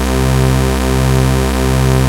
OSCAR 1  C3.wav